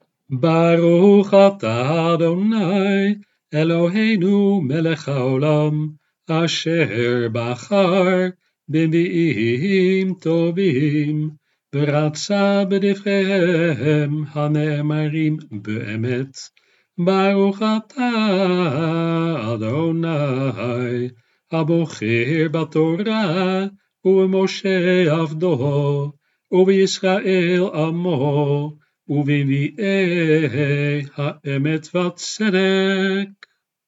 De opnames van teksten zijn in de mannelijke vorm.
Berachot bij het lezen van de Haftara